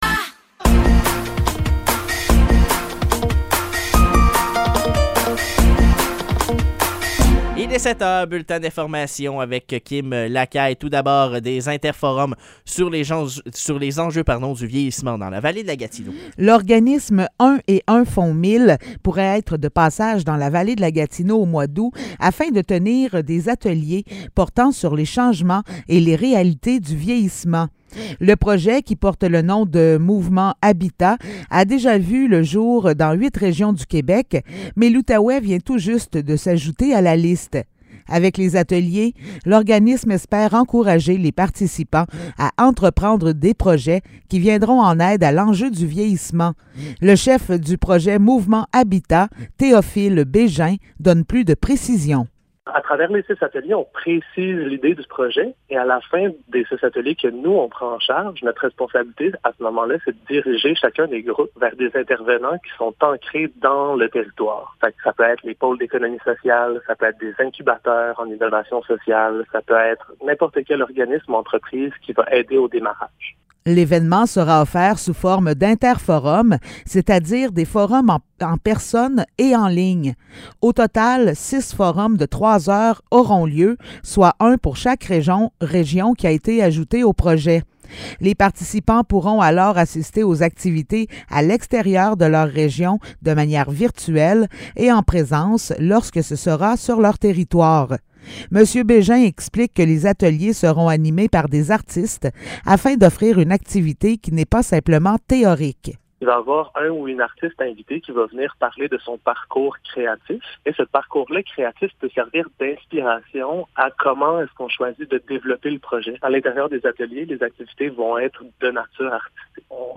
Nouvelles locales - 31 mai 2023 - 7 h